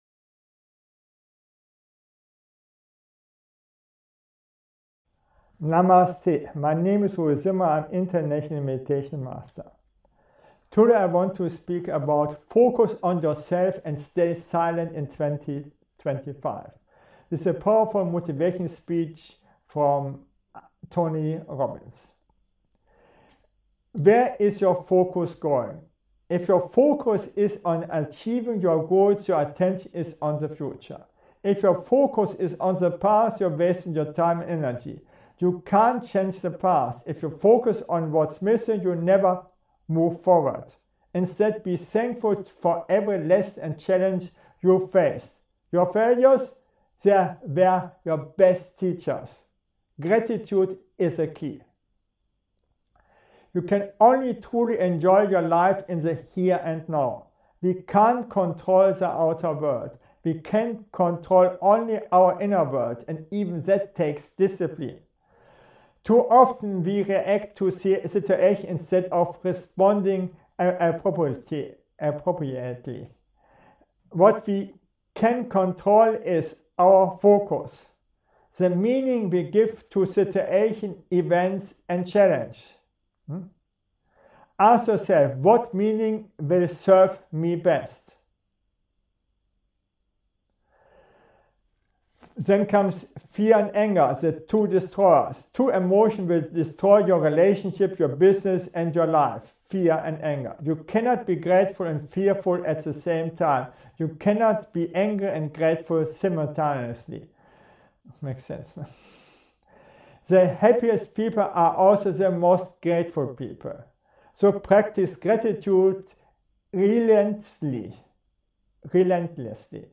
Powerful Motivational Speech | Inspired by Tony Robbins 1.